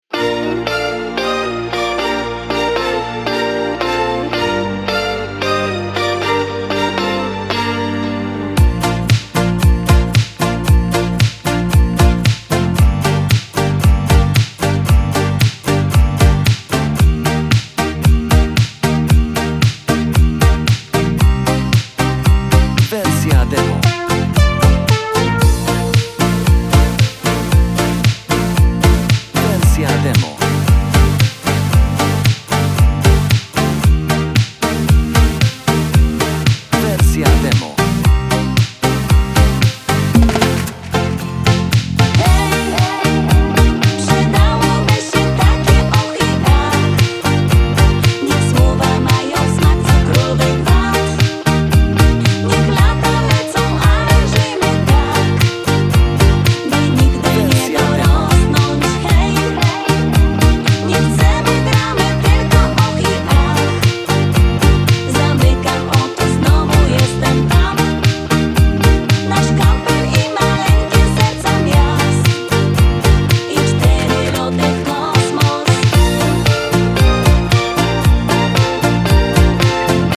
Podkłady dla wykonawców weselnych
Z chórkiem nagranym w refren lub bez chórku.